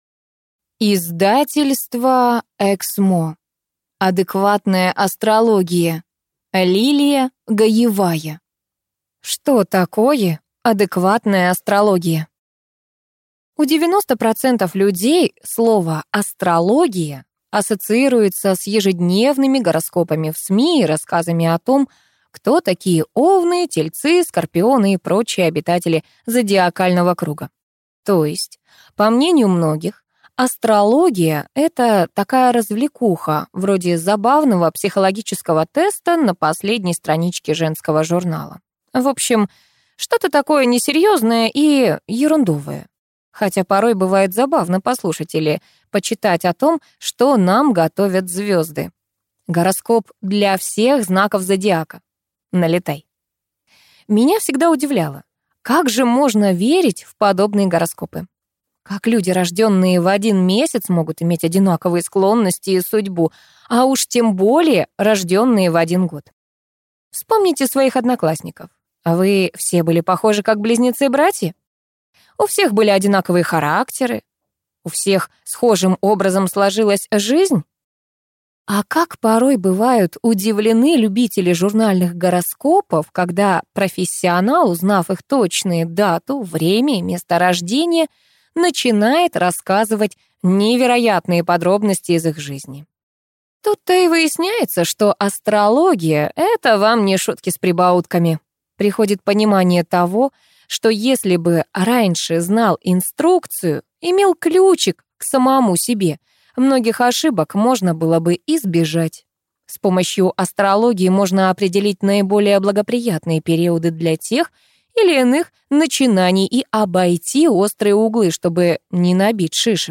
Аудиокнига Адекватная астрология | Библиотека аудиокниг
Прослушать и бесплатно скачать фрагмент аудиокниги